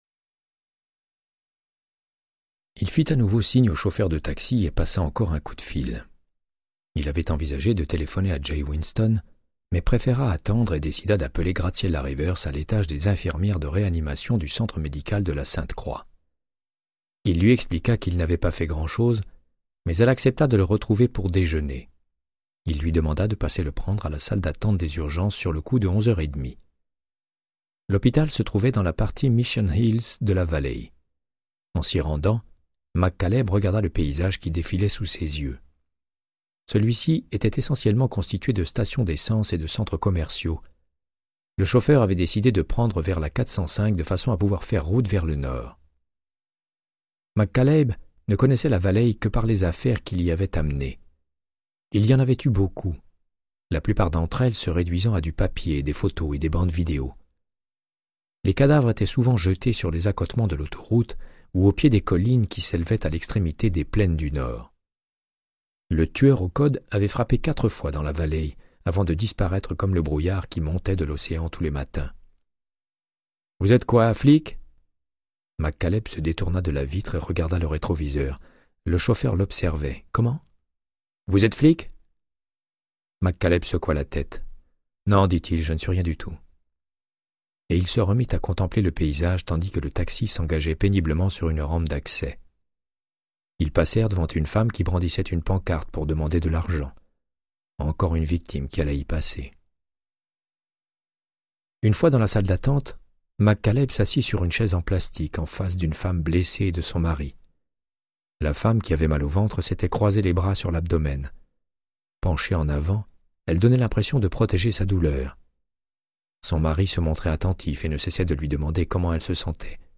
Audiobook = Créance de sang, de Michael Connellly - 24